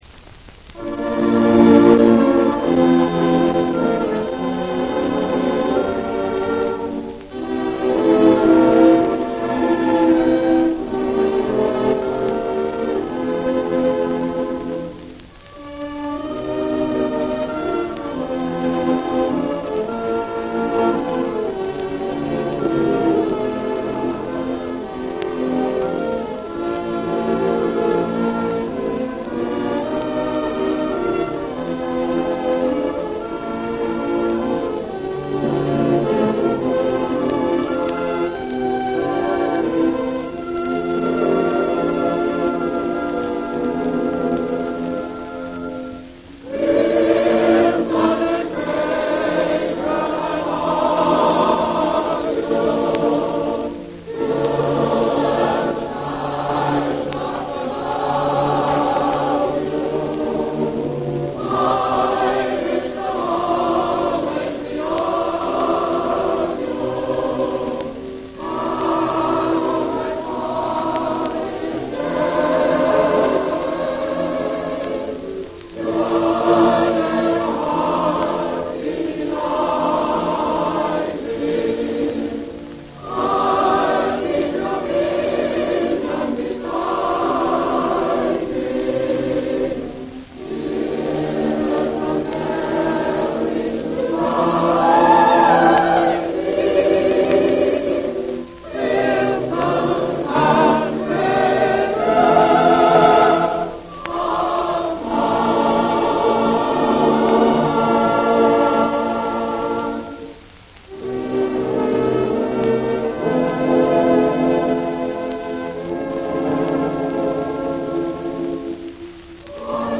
Recorded live for NBC Radio on May 15, 1934, at the Davenport Ballroom in Spokane performed by the Washington State College band and glee club.